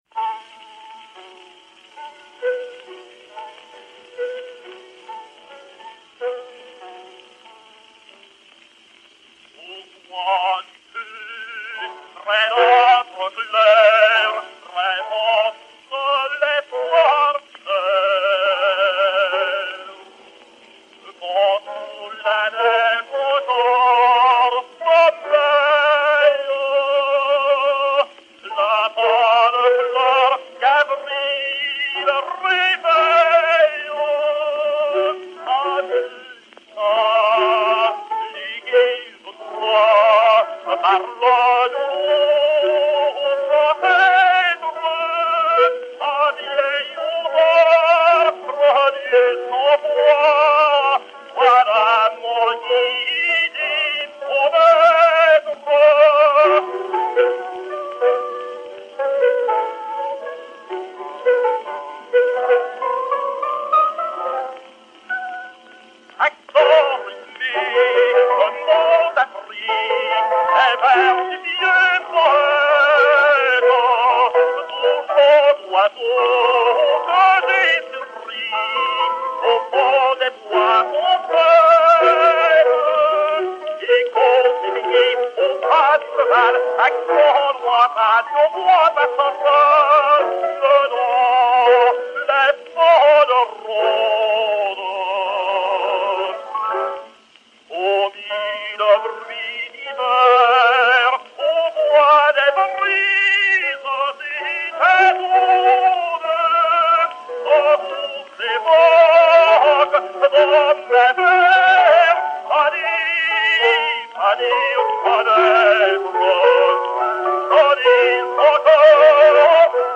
Grand air
Ernest Van Dyck (Walther) et Piano
Pathé 798, enr. à Londres en 1903